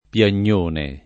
piagnone [ p L an’n’ 1 ne ]